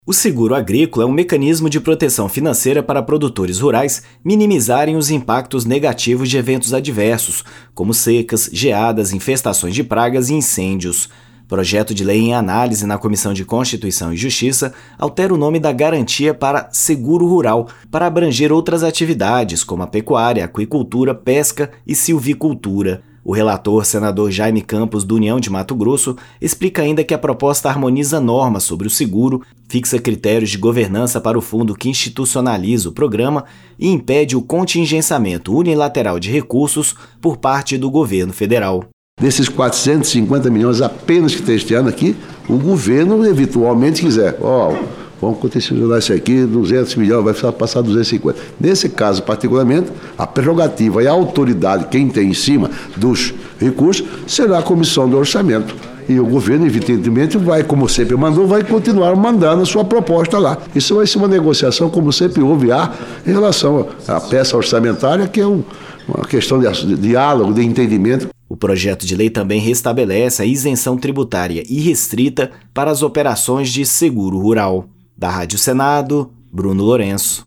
O relator, senador Jayme Campos (União-MT), defende que o projeto harmoniza normas e impede o contingenciamento unilateral de recursos por parte do Governo Federal.